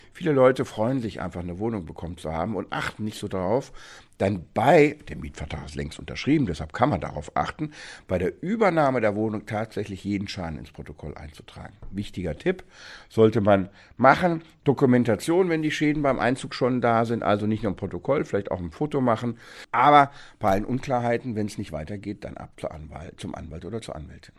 O-Ton Kaution für die Wohnung – was dürfen Vermieter? Was müssen Mieter? – Vorabs Medienproduktion